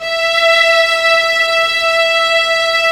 Index of /90_sSampleCDs/Roland LCDP13 String Sections/STR_Violas II/STR_Vas4 Amb f